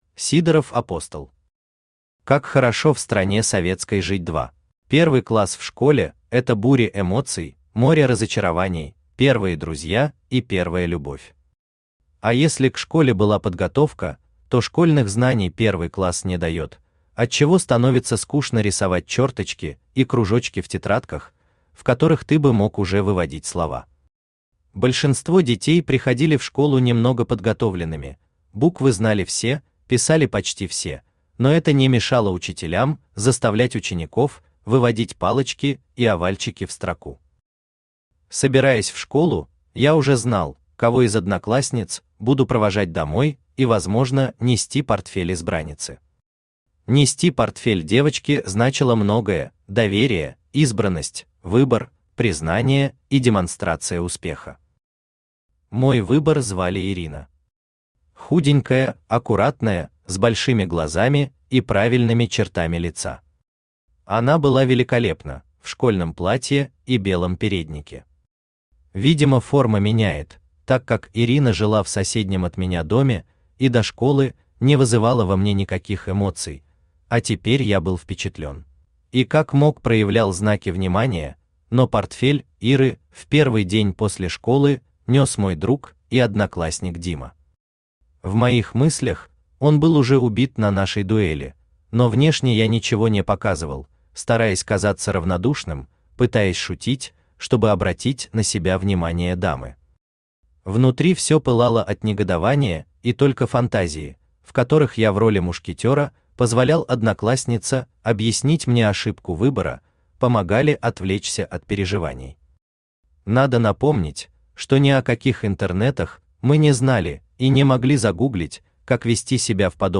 Аудиокнига Как хорошо в стране советской жить 2 | Библиотека аудиокниг
Aудиокнига Как хорошо в стране советской жить 2 Автор Сидоров-Апостол Читает аудиокнигу Авточтец ЛитРес.